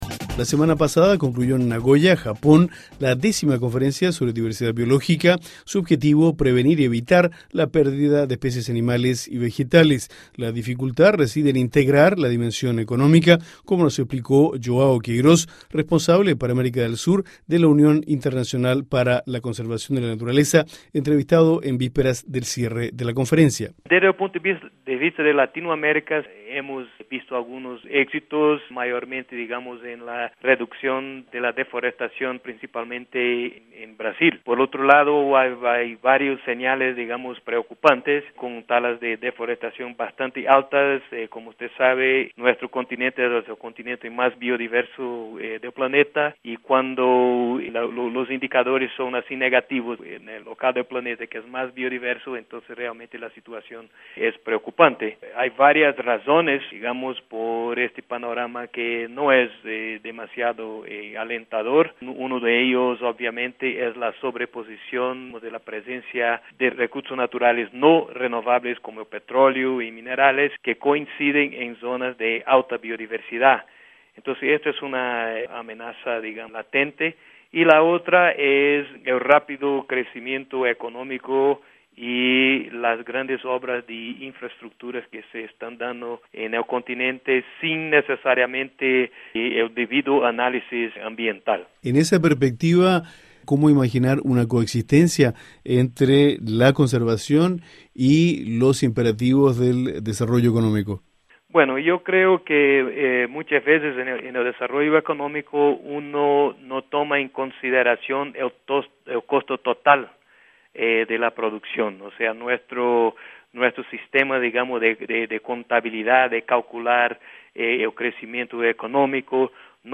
Se realizó en Japón la 10ª conferencia sobre diversidad biológica. Escuche el informe de Radio Francia Internacional